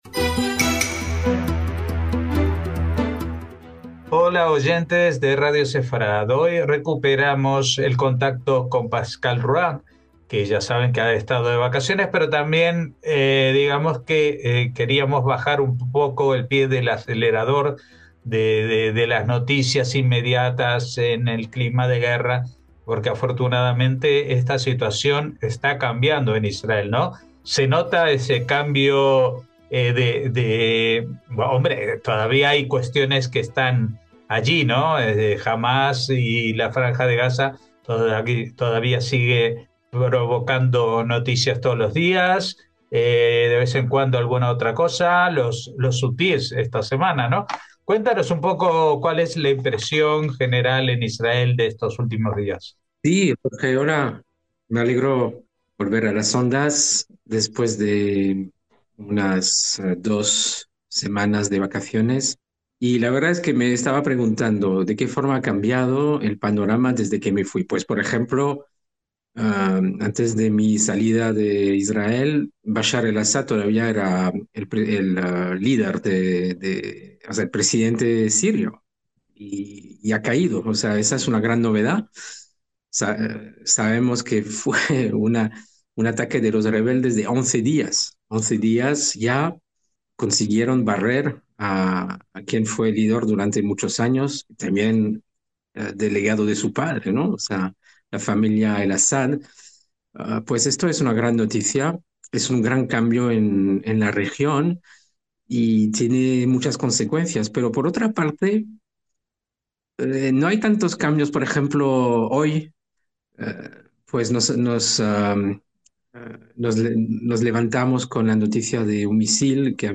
NOTICIAS CON COMENTARIO A DOS